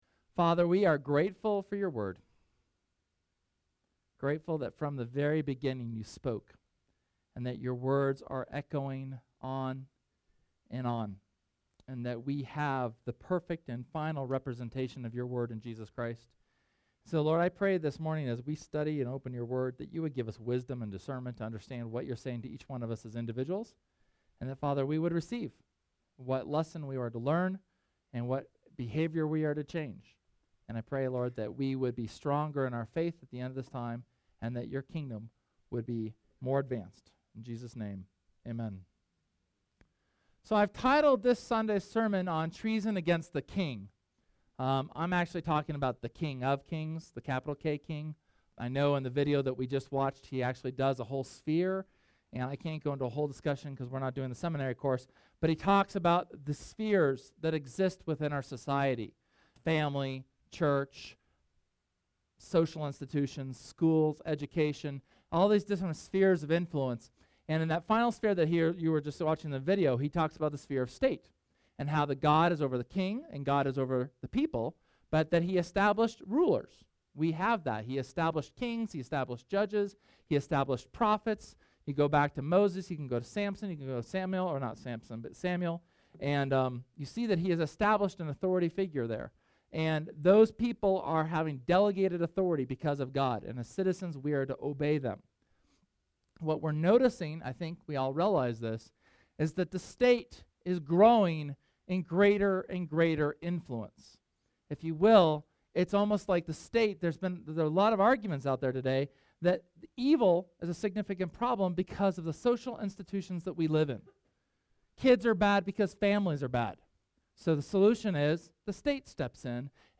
SERMON: Treason